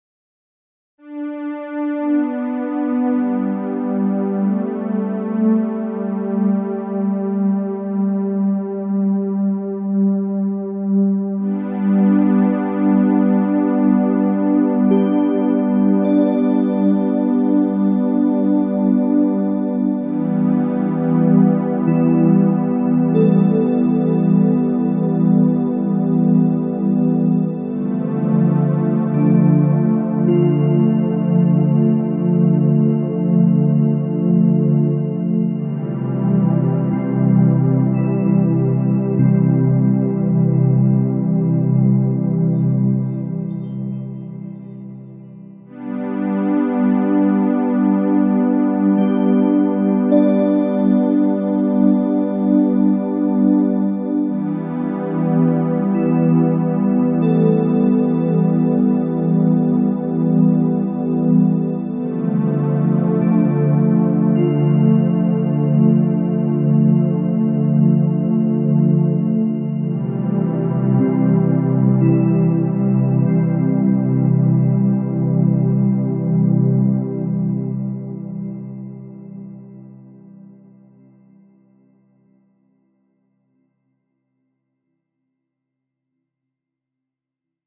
ショートニューエイジ